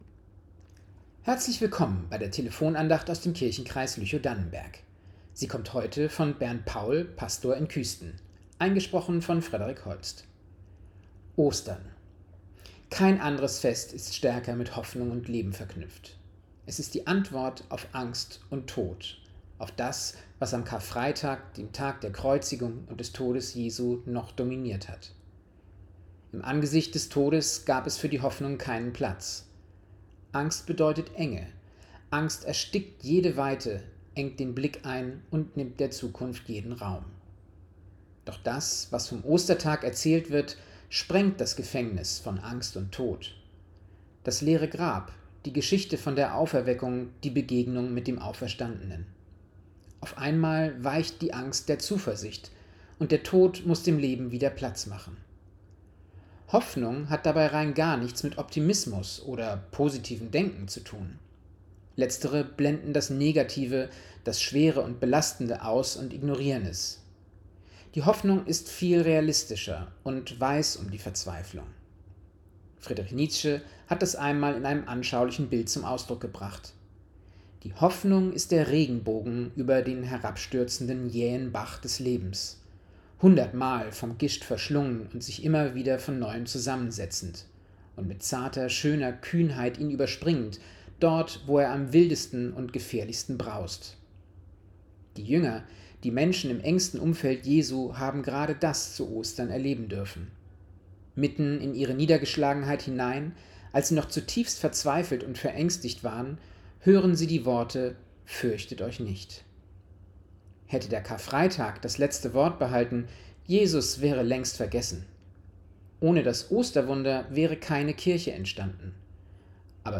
Telefon-Andachten des ev.-luth. Kirchenkreises Lüchow-Dannenberg